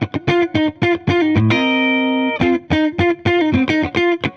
Index of /musicradar/dusty-funk-samples/Guitar/110bpm
DF_70sStrat_110-F.wav